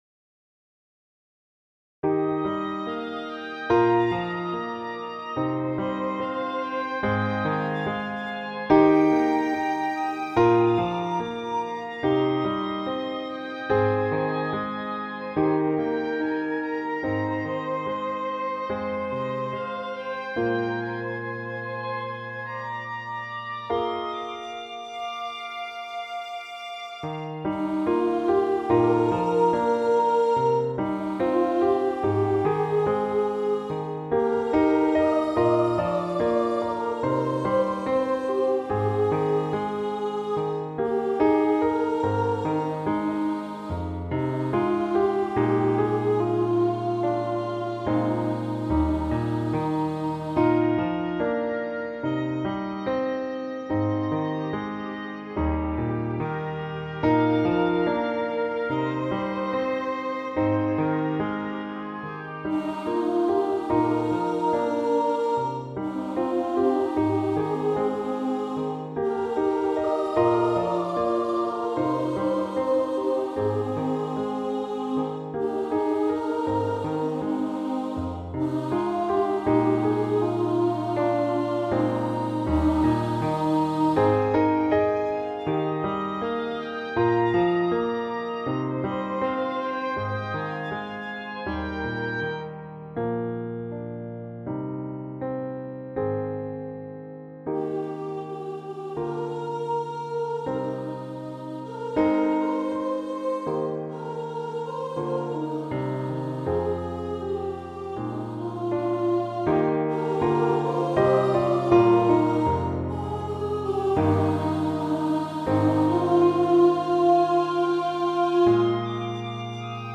• Music Type: Choral
• Voicing: SA
• Accompaniment: Flute, Oboe, Piano, Violin